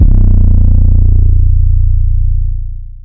BWB WAV 7 808 (5).wav